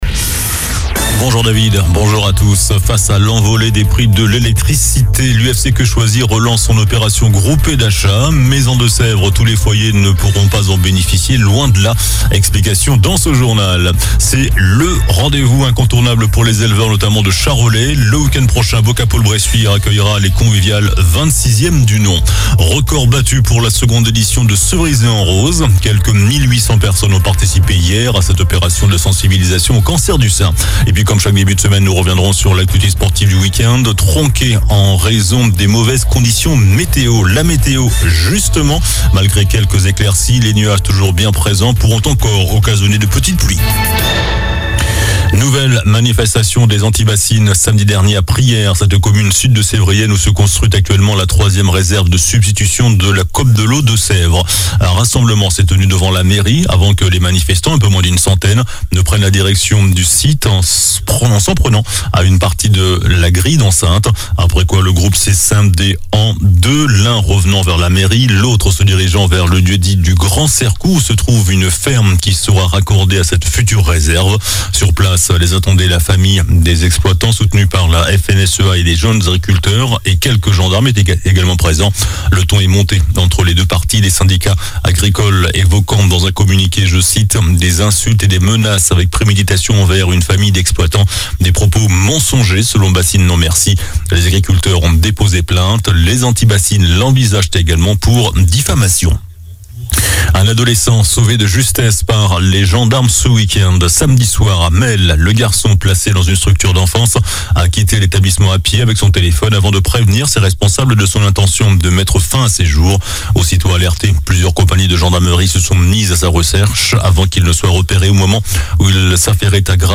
JOURNAL DU LUNDI 30 OCTOBRE ( MIDI )